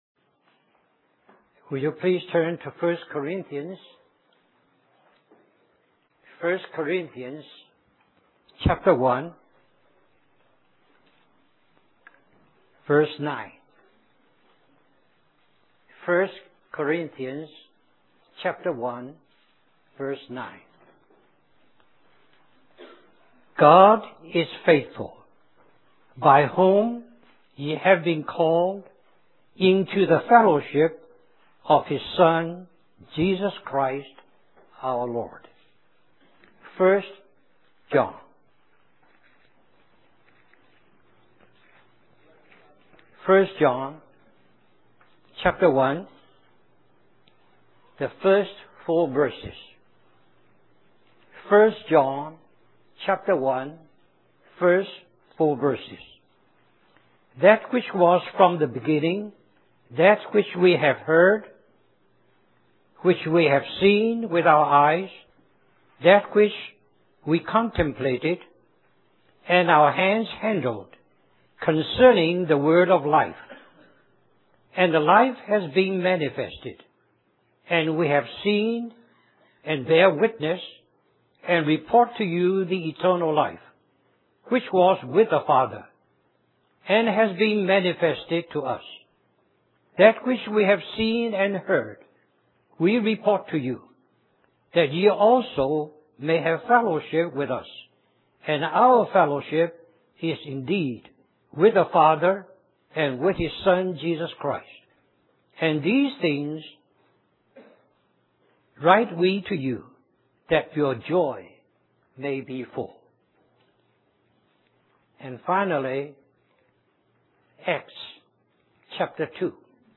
2002 Richmond, Virginia, US Stream or download mp3 Summary This message is the first of three in a series regarding fellowship. God is calling us into the fellowship of His Son.